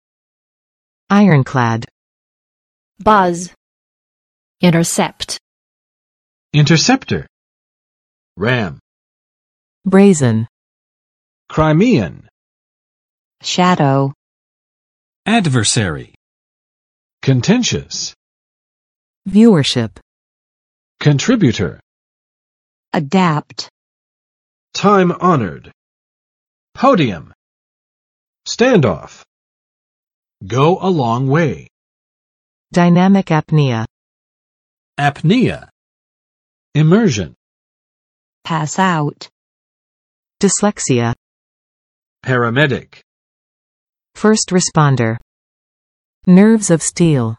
[ˋaɪɚn͵klæd] adj. 铁定的